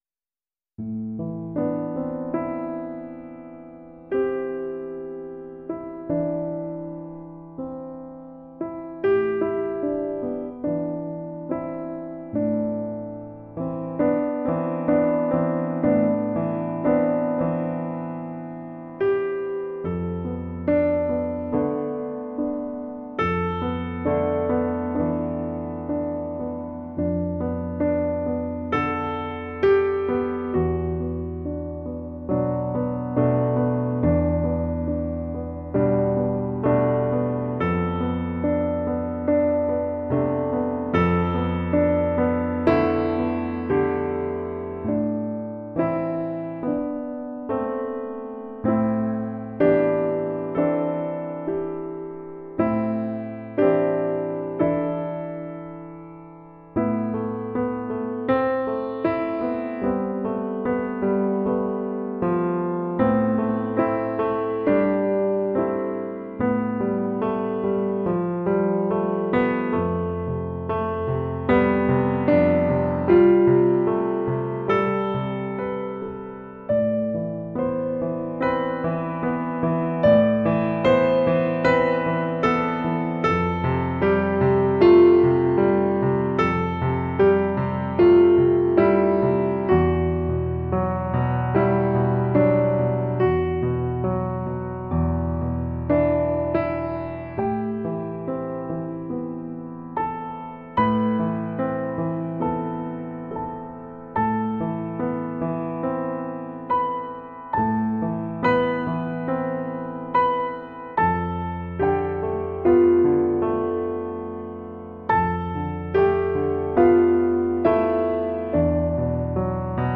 это завораживающий трек в жанре инди-поп
атмосферными инструментами и нежным вокалом